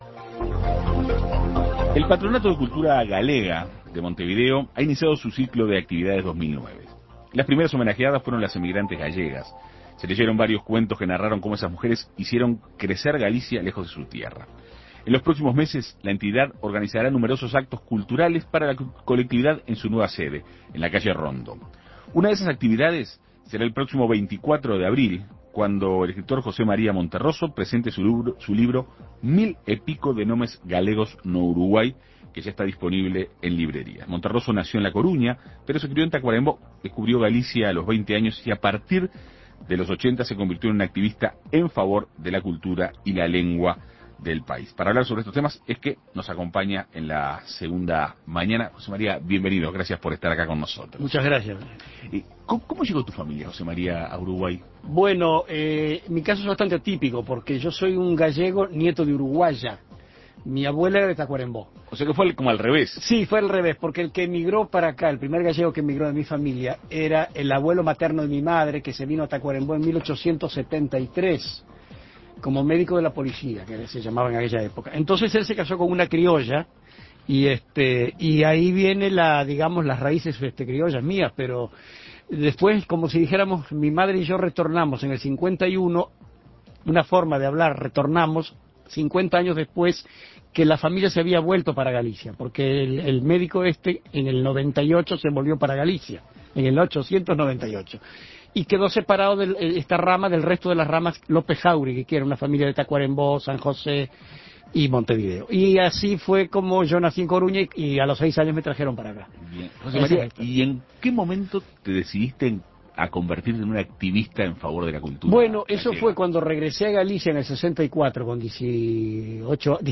En Perspectiva Segunda Mañana recibió al escritor para hablar sobre las familias gallegas vinculadas al Uruguay.